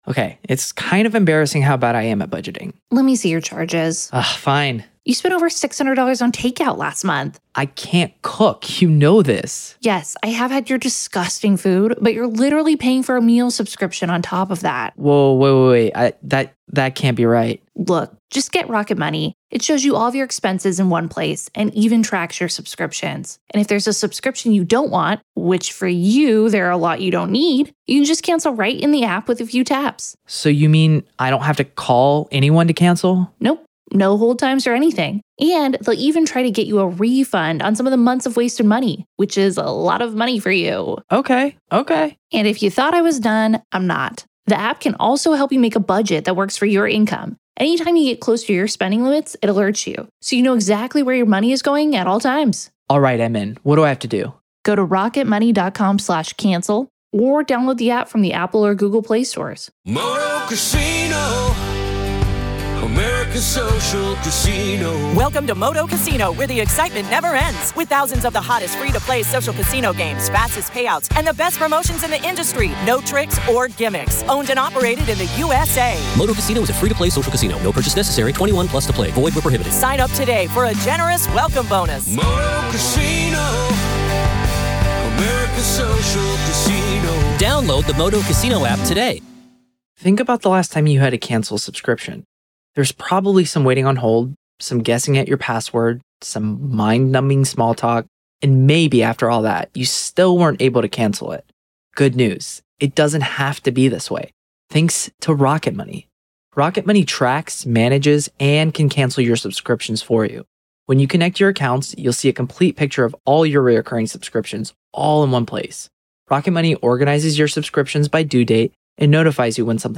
LIVE COURTROOM COVERAGE — NO COMMENTARY
This series provides unfiltered access to the testimony, exhibits, expert witnesses, and courtroom decisions as they happen. There is no editorializing, no added narration, and no commentary — just the court, the attorneys, the witnesses, and the judge.